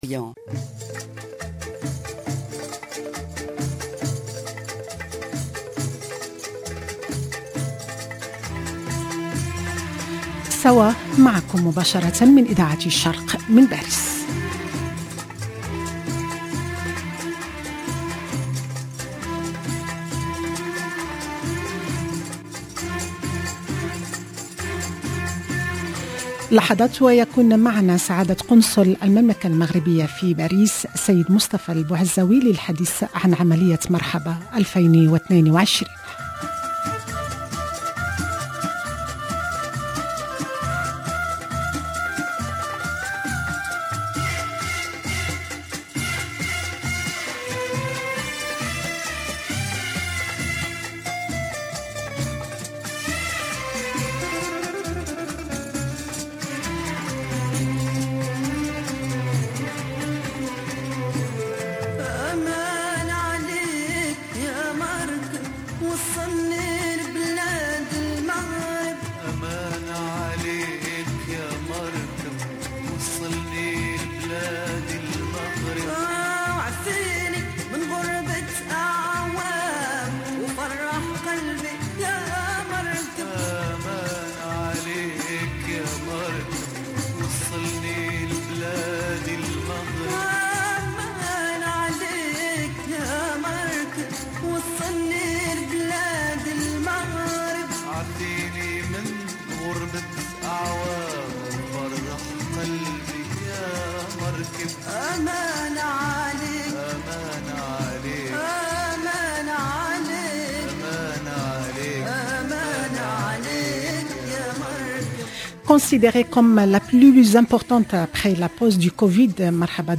l'invité de SAWA sur Radio Orient Mr Mustapha ELBOUAZZAOUI, consul général du Maroc à Paris.